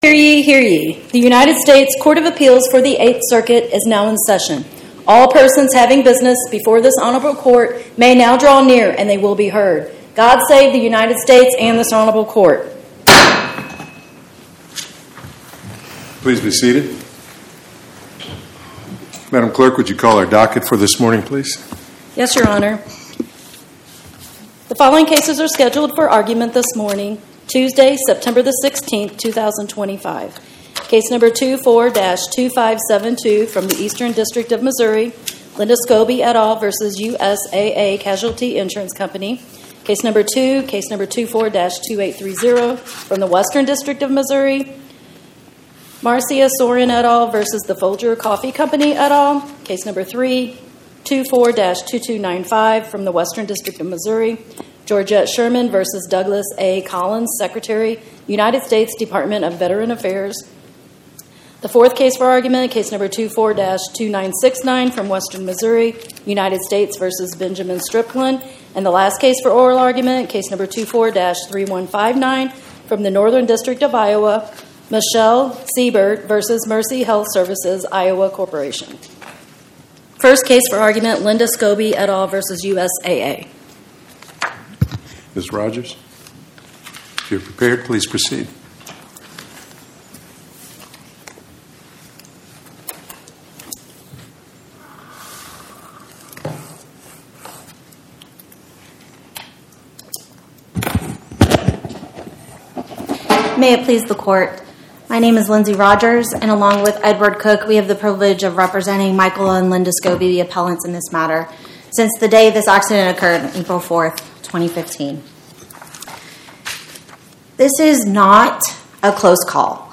Oral argument argued before the Eighth Circuit U.S. Court of Appeals on or about 09/16/2025